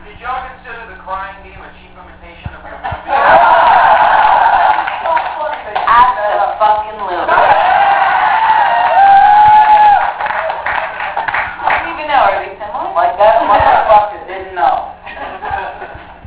Clip 9: The audience asks if everyone felt "THE CRYING GAME" was a cheap imitation of SLEEPAWAY CAMP!!